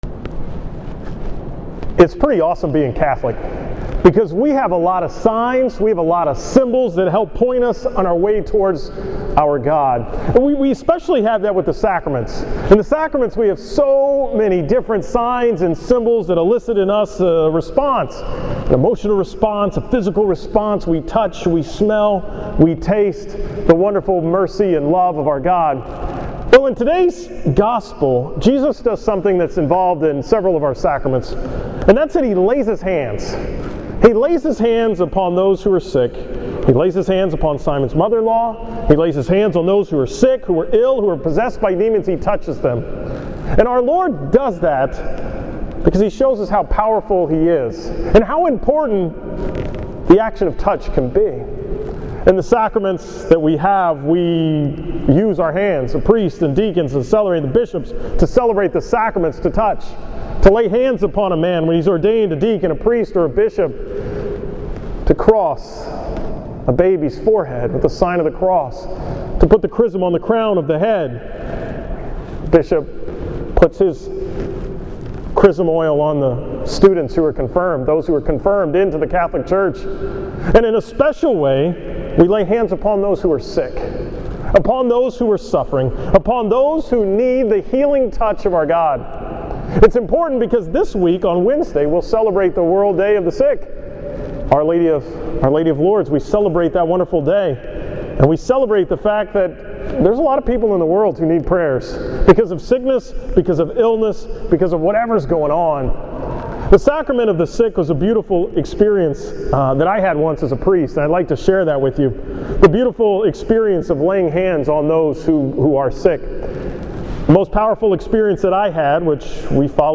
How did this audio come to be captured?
From the 5:30 pm Mass at St. John Vianney on Sunday, February 8, 2015